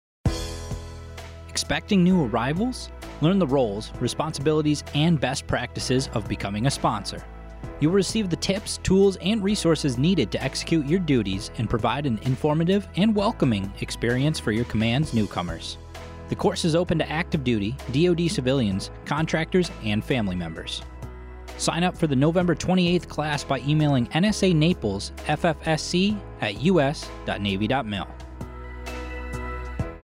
Radio spot highlighting an upcoming sponsorship training course with Fleet & Family Service Center onboard NSA Naples.